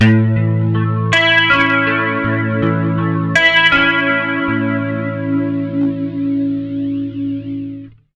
80MAJARP A-L.wav